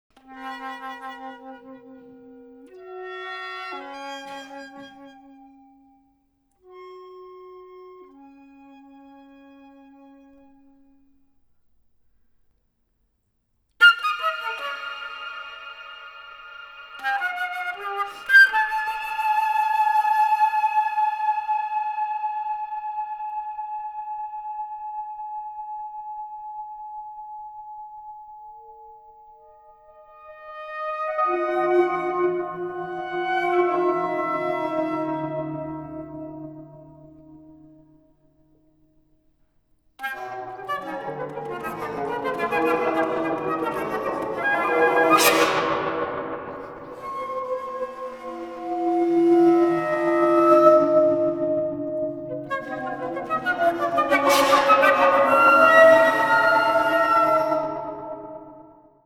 for flute and live electronics, 5 minutes, 2013.